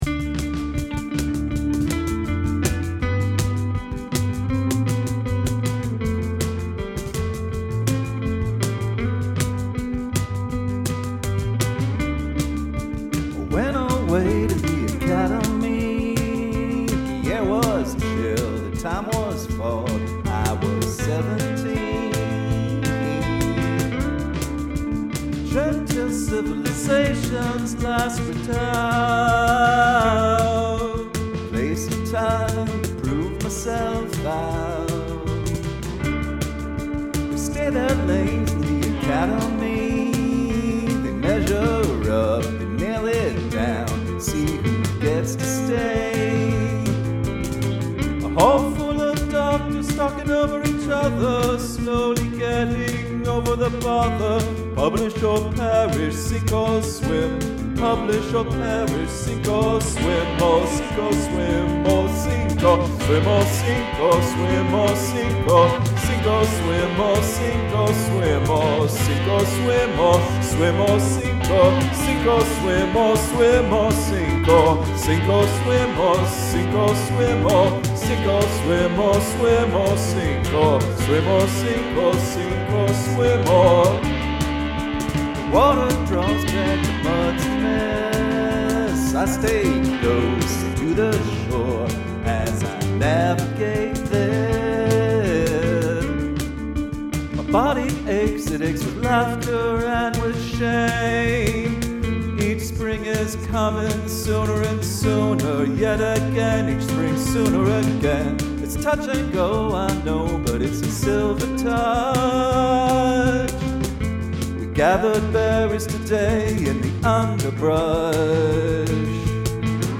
Short but sounds long due to never changing.
Is this just the same loop over and over?